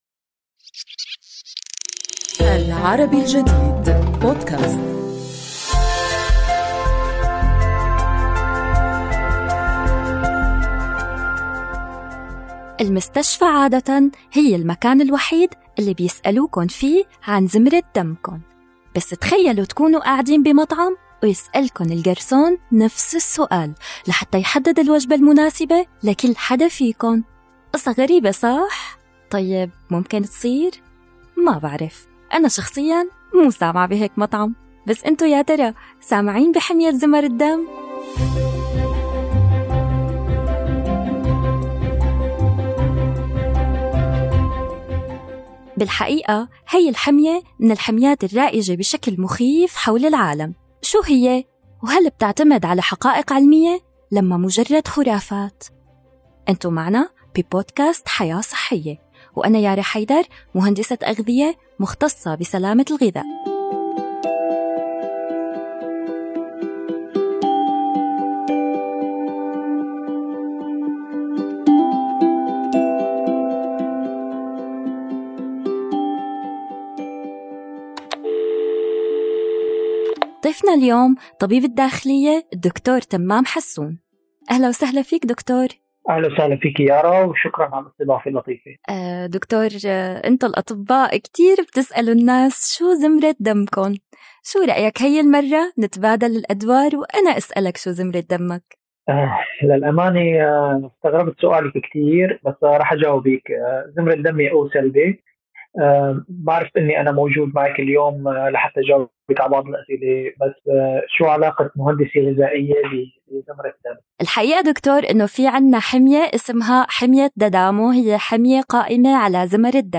طبيب الأمراض الداخلية